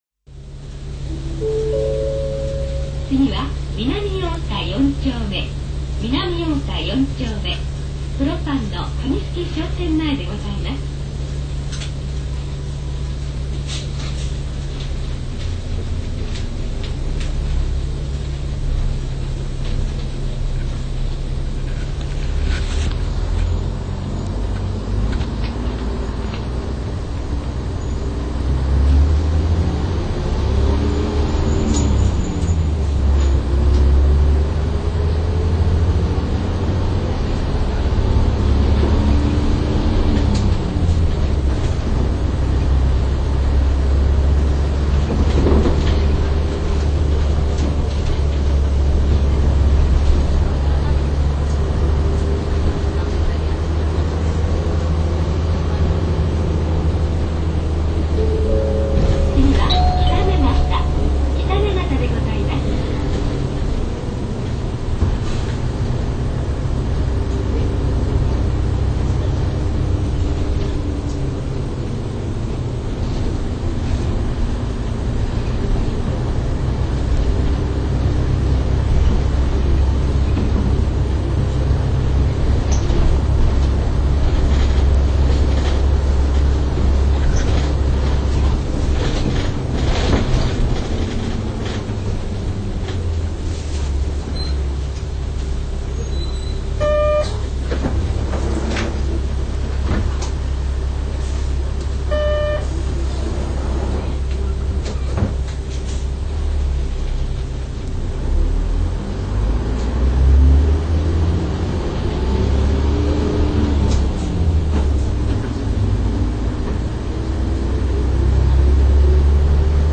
低音が強いのが特徴です。聴く際は低音をよく再生できるスピーカを推奨します。